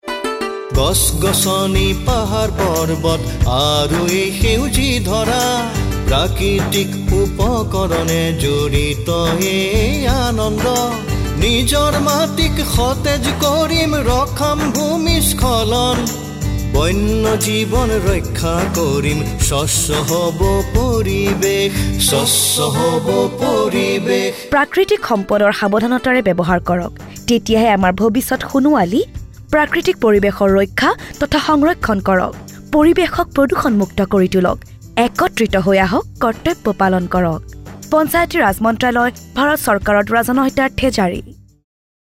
31 Fundamental Duty 7th Fundamental Duty Preserve natural environment Radio Jingle Assamese